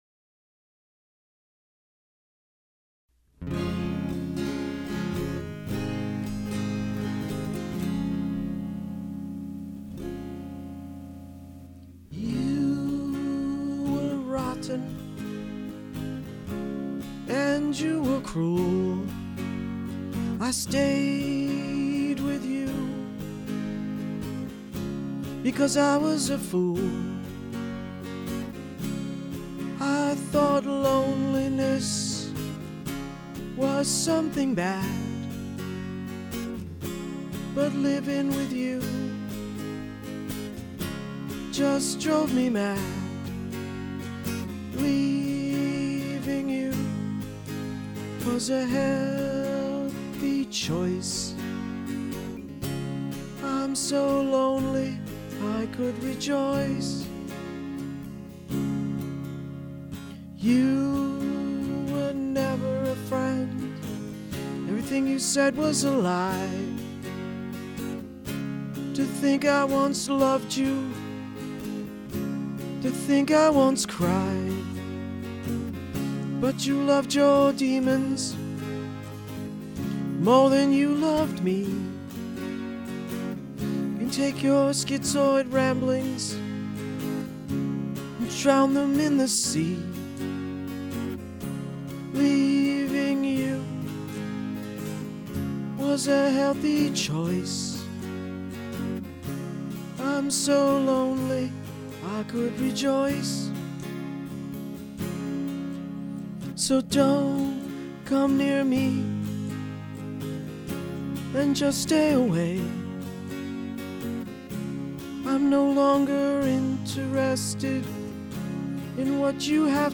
Recorded in Low-Fidelity December 2005-February 2006 at
Guitar
and  Drum Machine